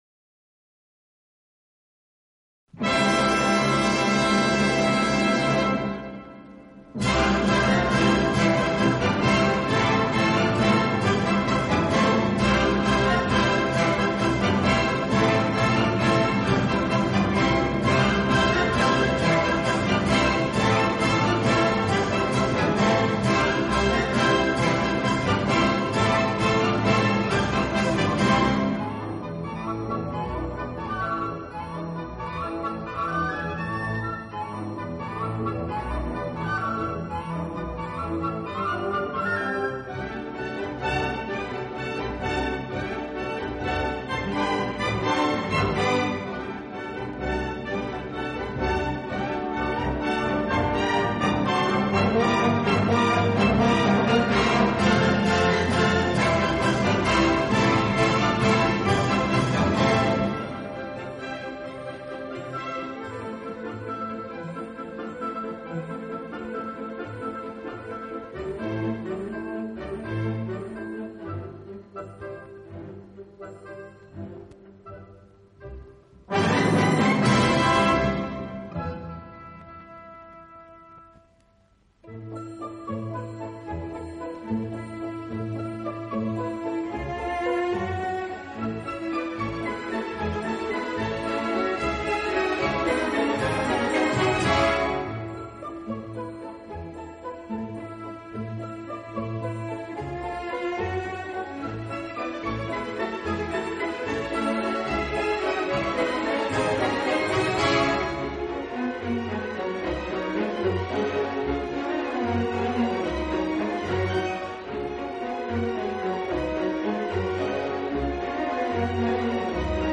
演奏以轻音乐和舞曲为主。
已是83岁高龄，但他的音乐仍然洋溢着青春的律动。